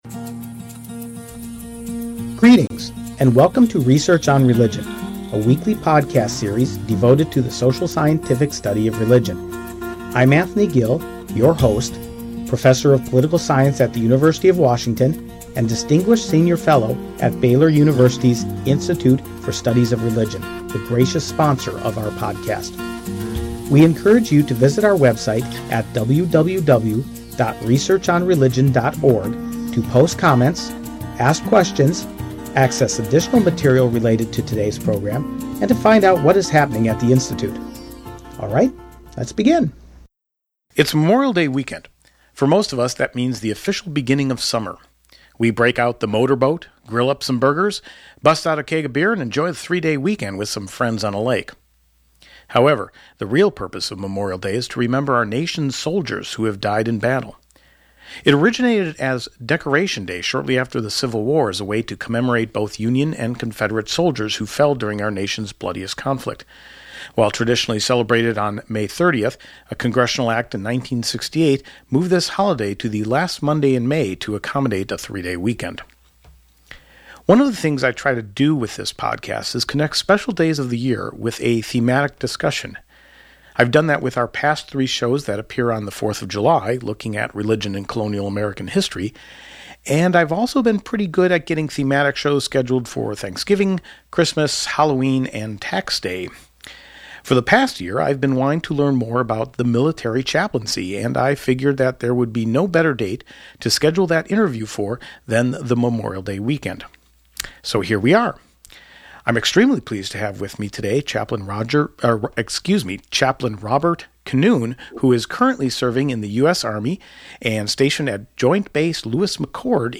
This interview represents another in our occasional series where we examine the life story of someone who makes a career out of religious service.